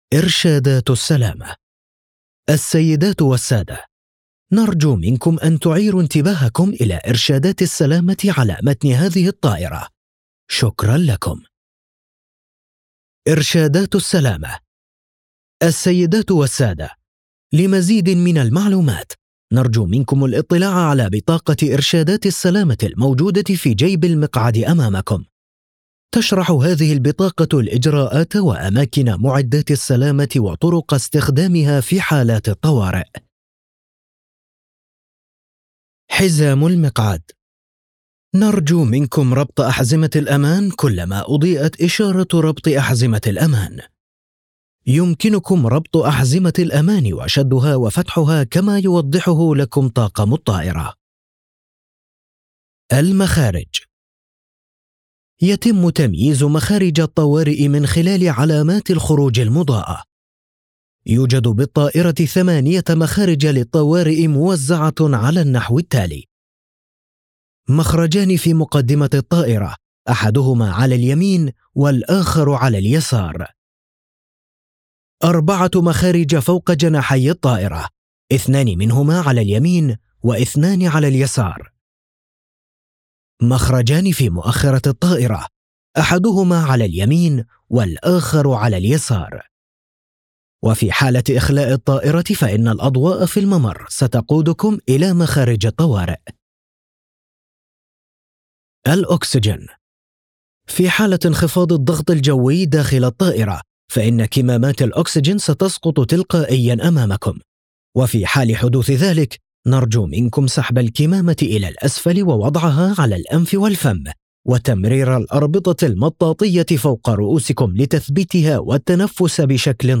Explainer & Whiteboard Video Voice Overs
Yng Adult (18-29) | Adult (30-50)